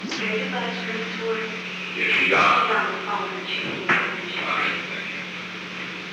Secret White House Tapes
Conversation No. 509-17
Location: Oval Office
The President met with an unknown woman